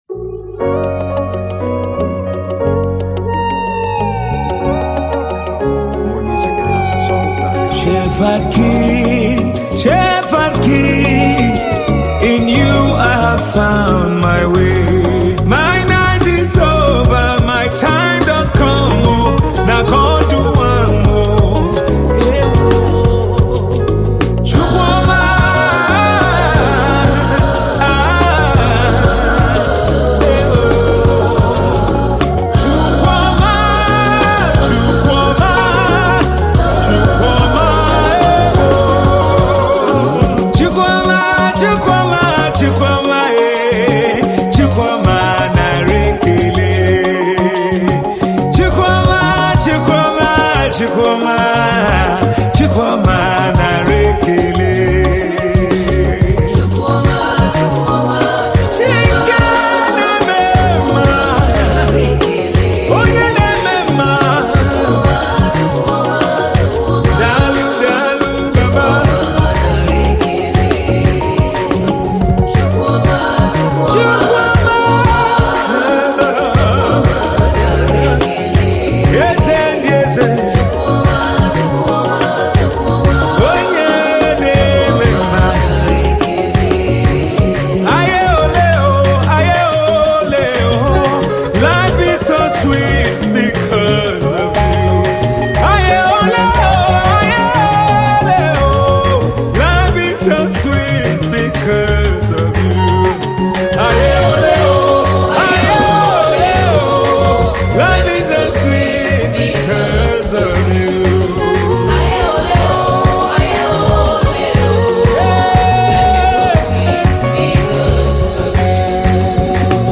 Tiv songs